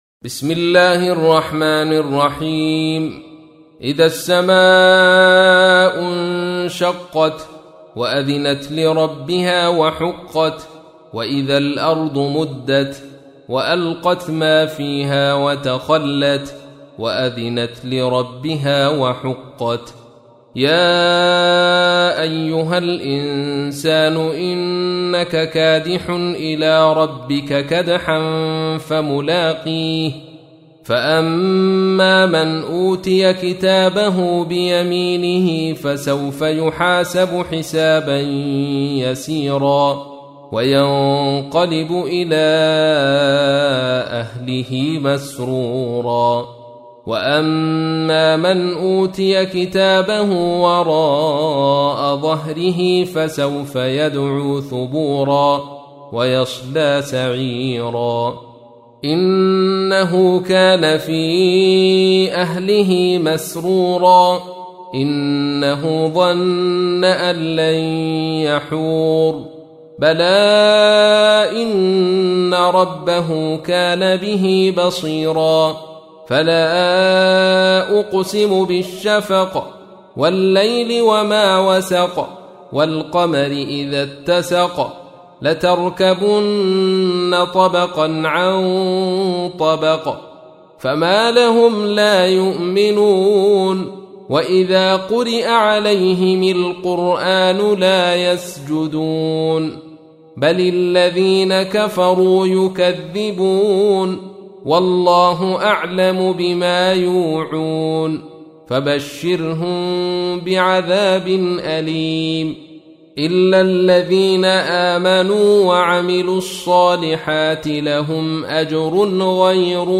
تحميل : 84. سورة الانشقاق / القارئ عبد الرشيد صوفي / القرآن الكريم / موقع يا حسين